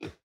Minecraft Version Minecraft Version 25w18a Latest Release | Latest Snapshot 25w18a / assets / minecraft / sounds / mob / armadillo / ambient7.ogg Compare With Compare With Latest Release | Latest Snapshot